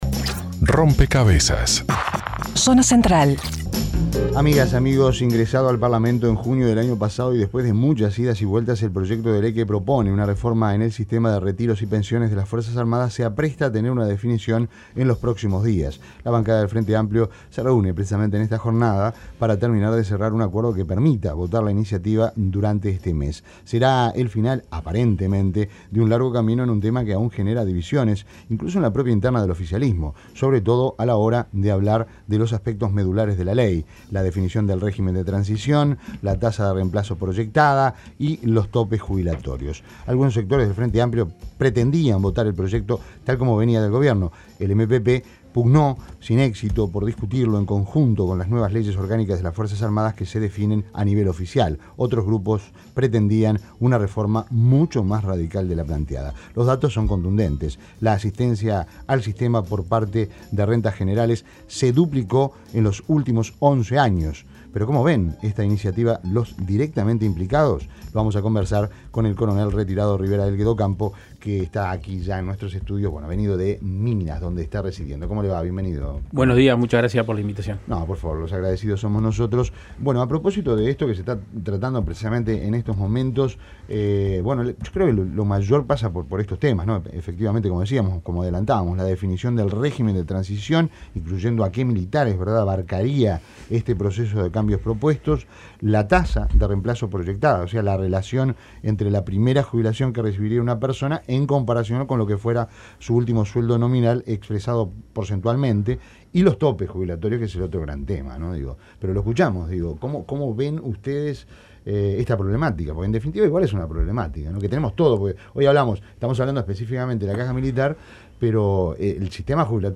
Entrevistado en Rompkbzas